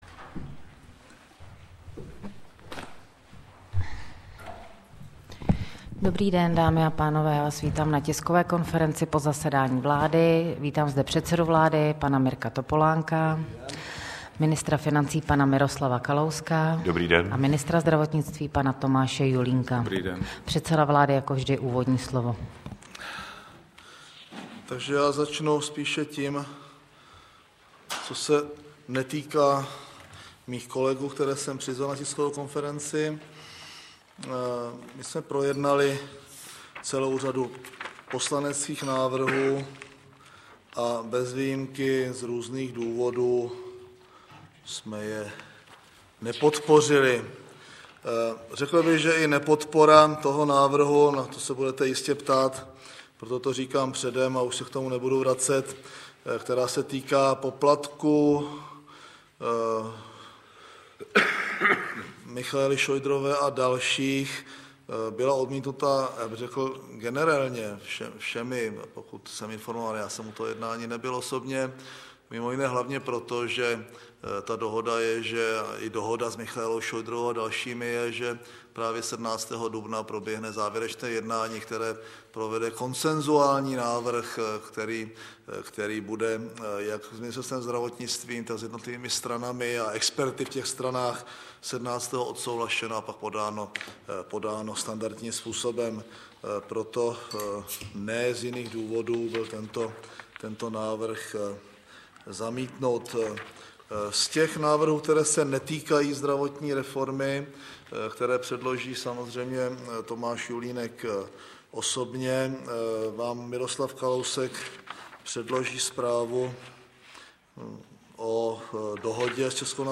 Tisková konference po jednání vlády ČR 9. dubna 2008